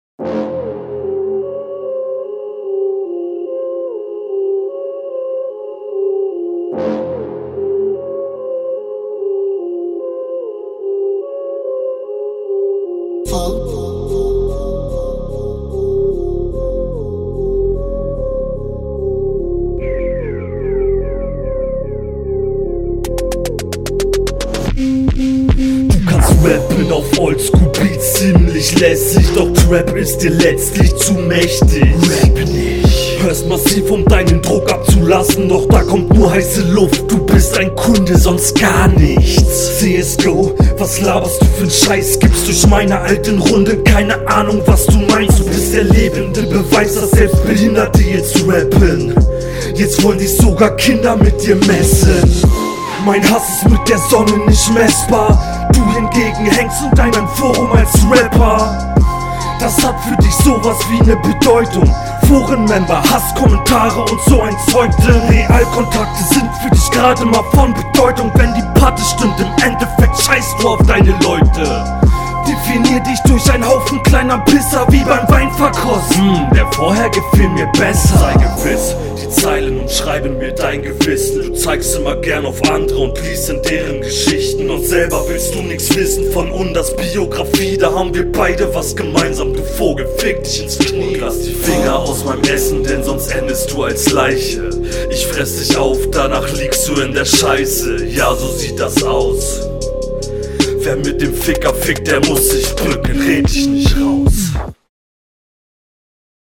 Du kommst überhaupt nicht auf dem Beat